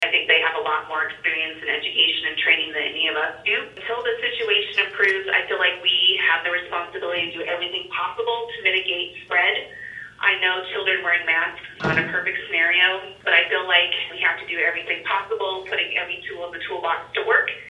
The board held a special meeting Wednesday night to adopt the plan before the start of classes.
Board Member Kristin Brighton, attending the meeting via phone call, says she was in favor because the medical advisory board gave her no reason not to respect their opinion.